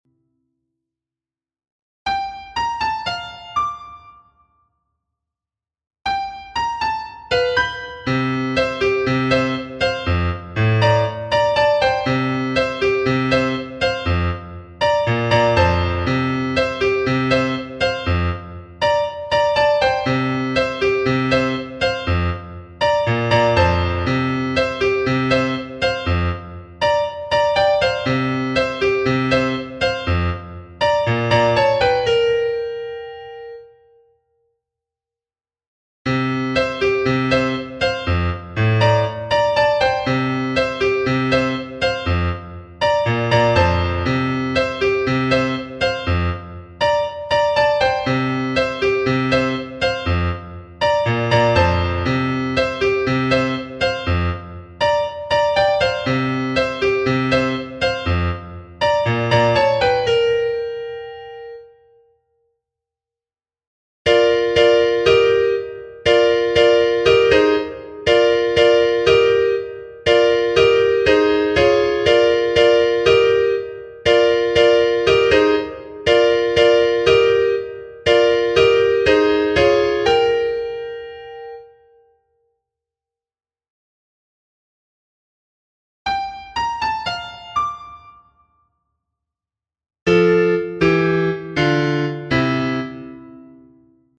January_1st_Parts-Piano.mp3